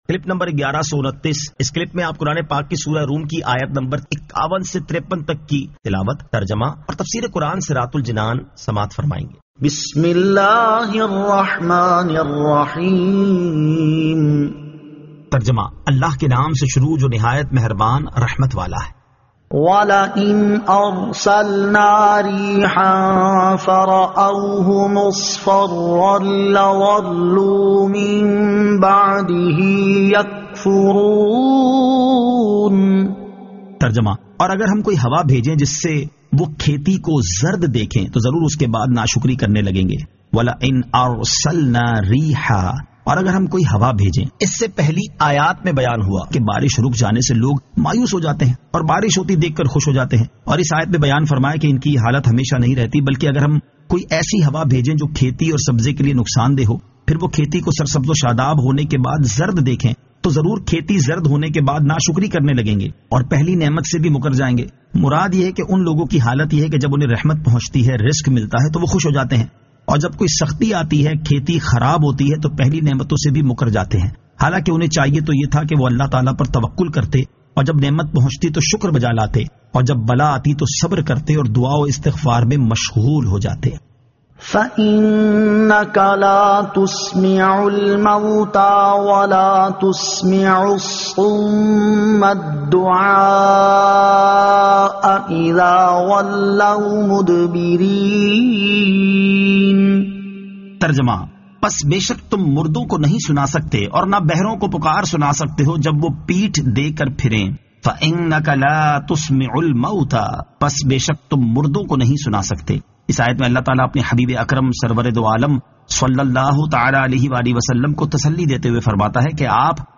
Surah Ar-Rum 51 To 53 Tilawat , Tarjama , Tafseer